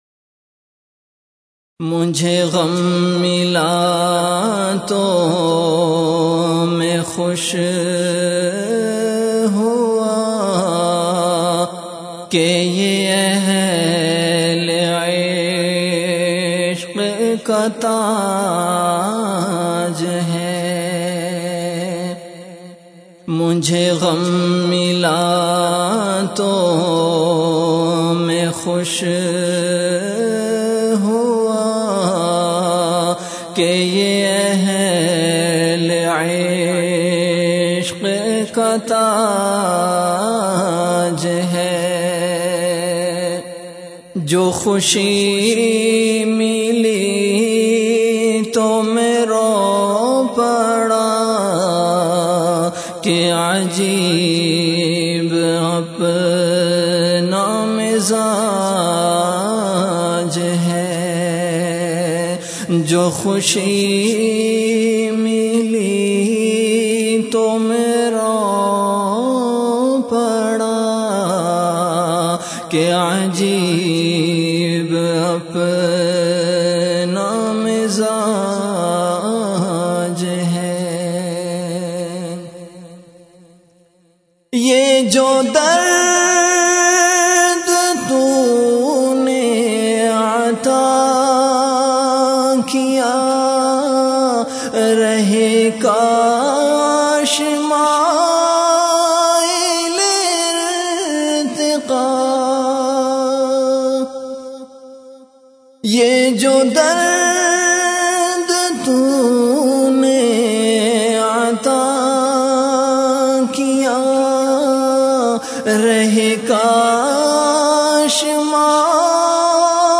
CategoryAshaar
VenueKhanqah Imdadia Ashrafia
Event / TimeAfter Isha Prayer